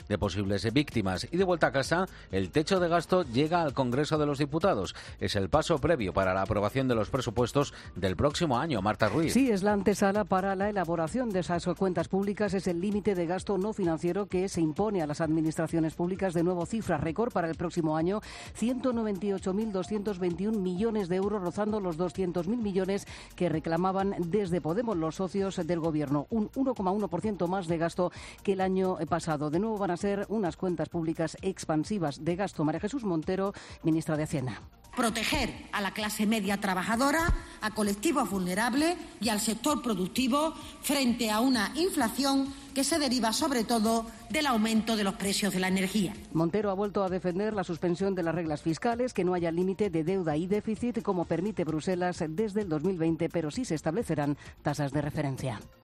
El Congreso debate el último paso para la aprobación de los Presupuestos 2023. Crónica